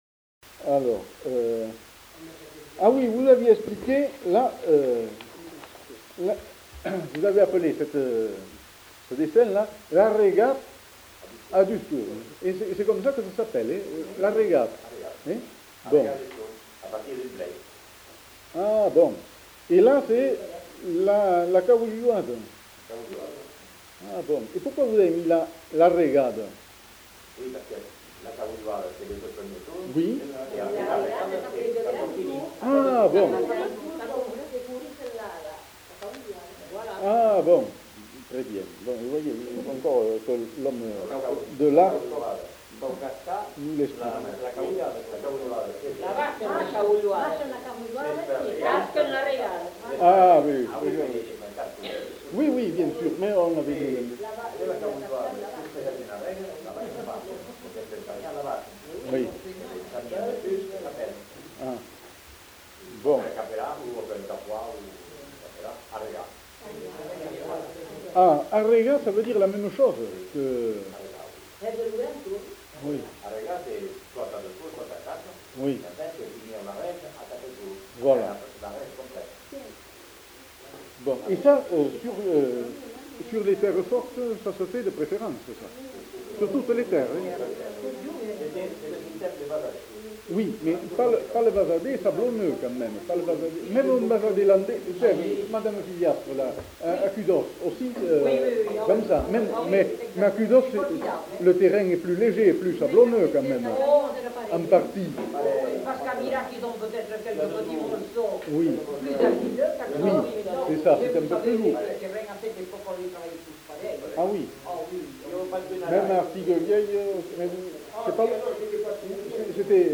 Genre : témoignage thématique
[enquêtes sonores]